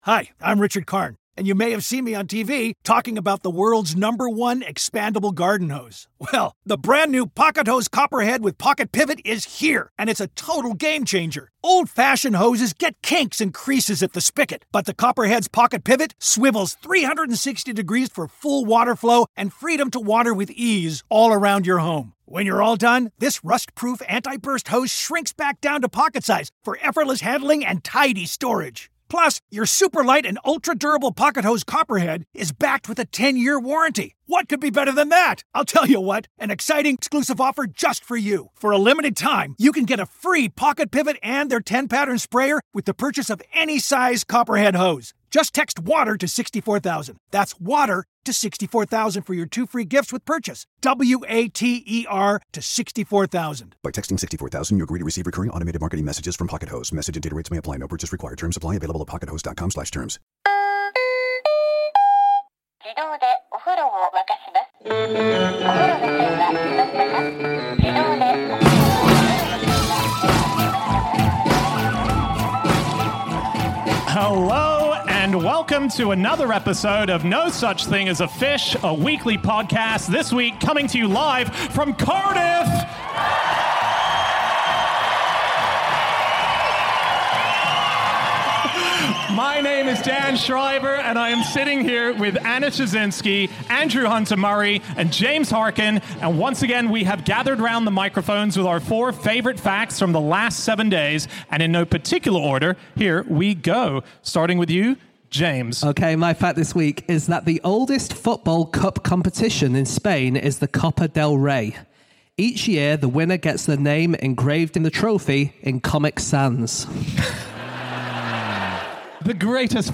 Live from Cardiff